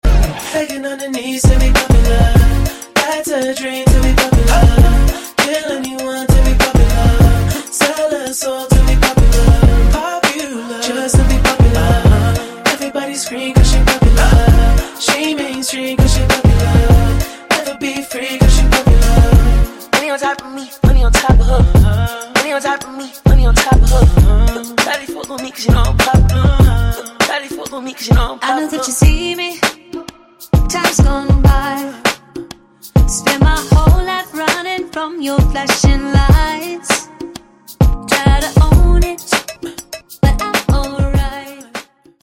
Pop & Rock